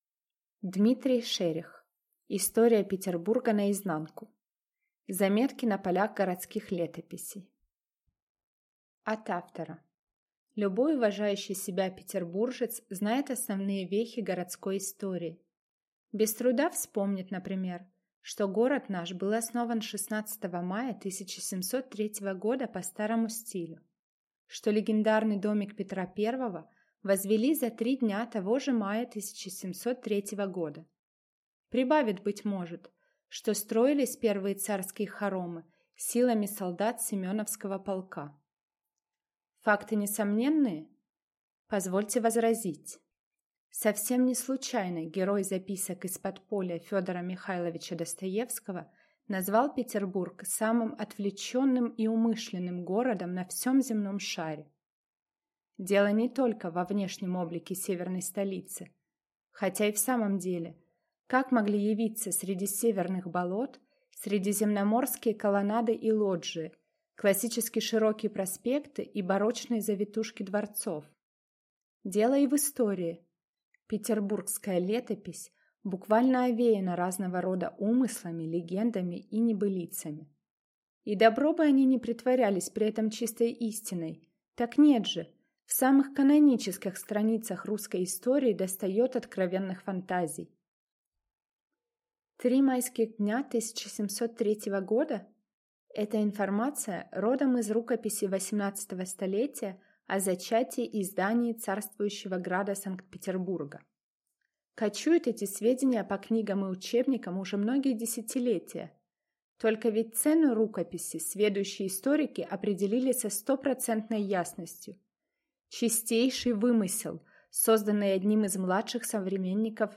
Аудиокнига История Петербурга наизнанку. Заметки на полях городских летописей | Библиотека аудиокниг